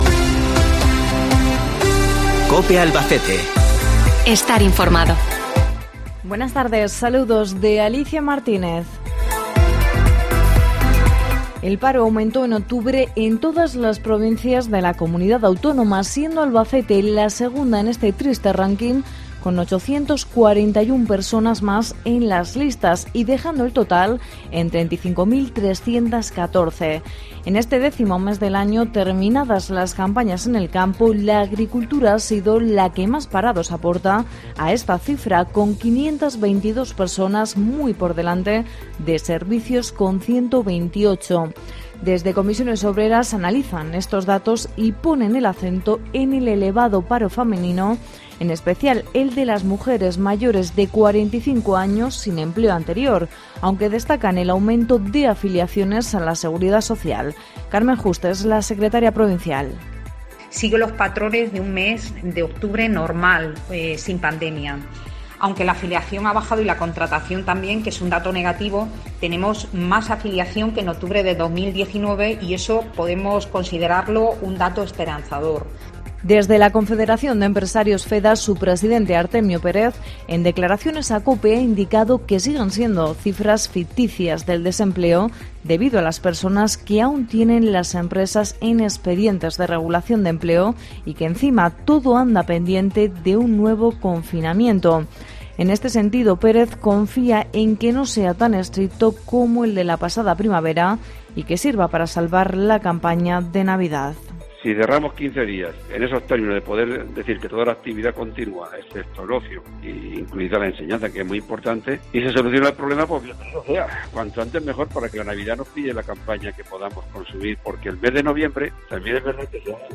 Informativo local 4